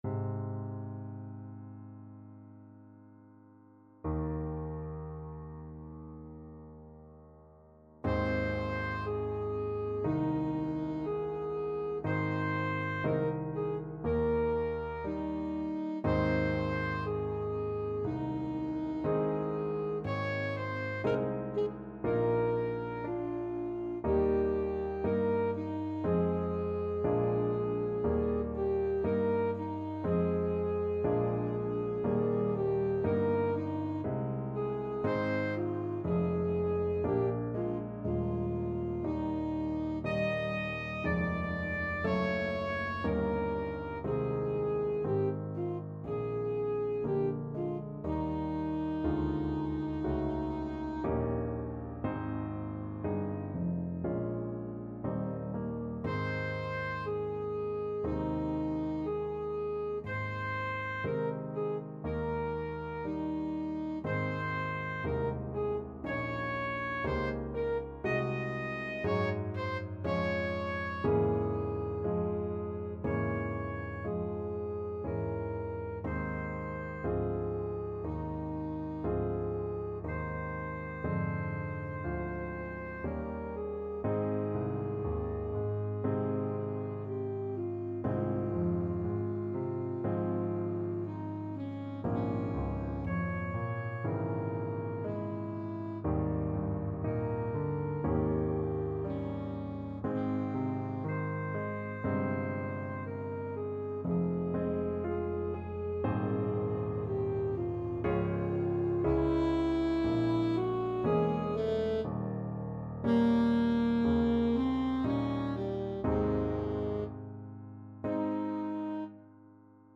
Classical Brahms, Johannes Violin Concerto, Op.77, Second Movement (Main Theme) Alto Saxophone version
Alto Saxophone
2/4 (View more 2/4 Music)
Ab major (Sounding Pitch) F major (Alto Saxophone in Eb) (View more Ab major Music for Saxophone )
~ = 100 Adagio =c.60
Classical (View more Classical Saxophone Music)